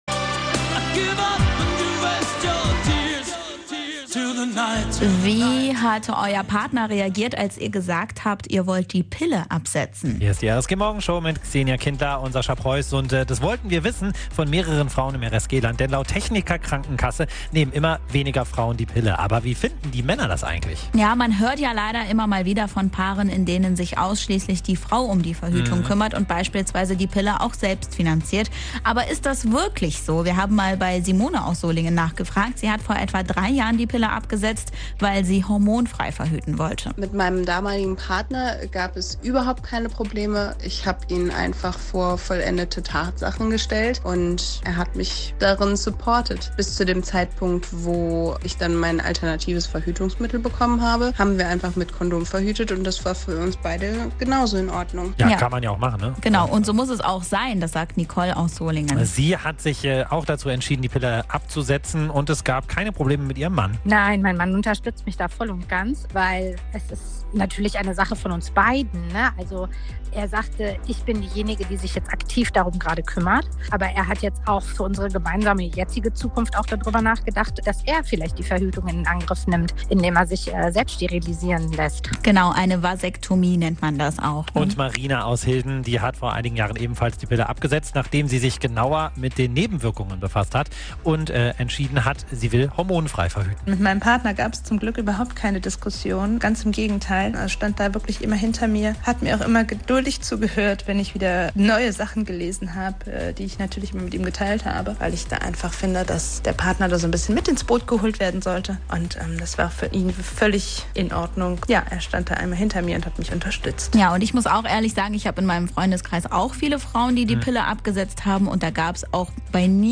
Wir haben mit Frauen aus dem RSG-Land über die Pille und alternative Verhütungsmethoden gesprochen.